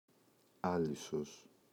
άλυσος, η [Ꞌalisos]